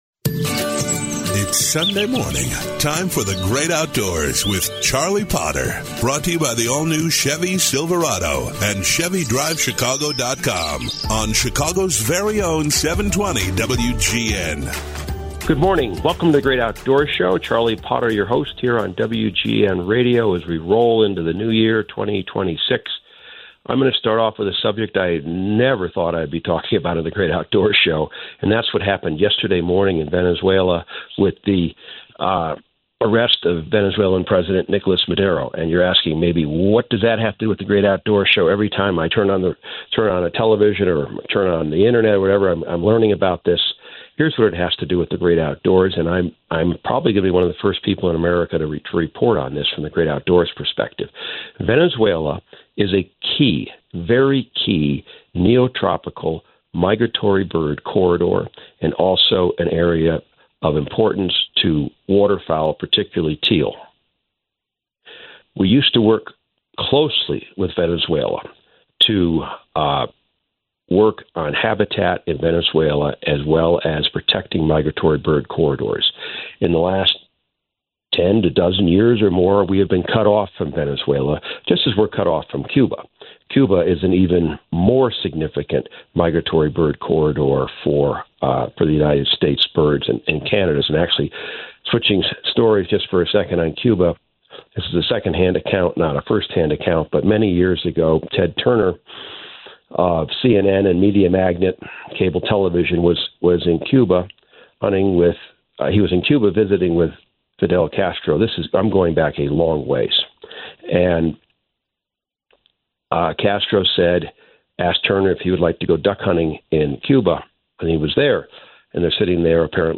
1 The Conservative Movement After 9/10: The Path Forward 38:14 Play Pause 8h ago 38:14 Play Pause Play later Play later Lists Like Liked 38:14 In this exclusive live episode from AmFest, Michael Knowles joins the show for a wide-ranging conversation on the state of the conservative movement and what comes next in the aftermath of 9/10. He breaks down the cultural and political challenges ahead and outlines how conservatives should think strategically about the future.